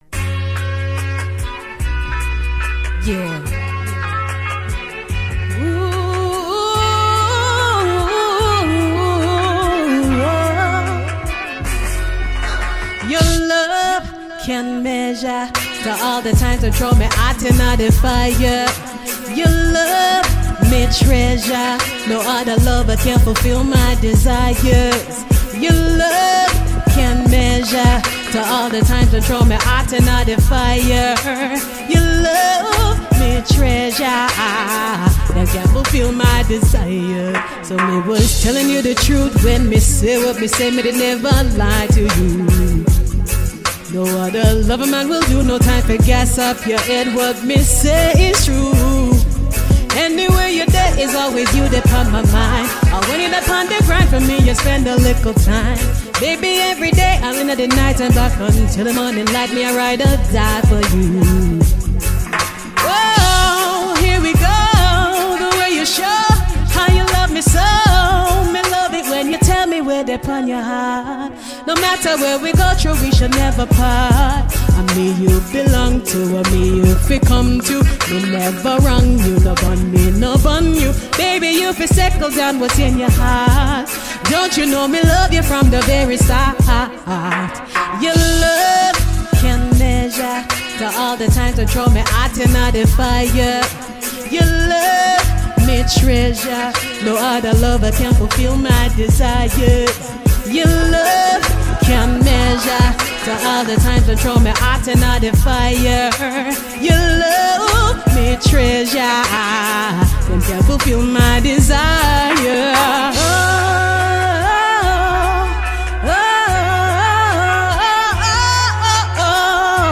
Reggae and Dancehall artiste
freestyle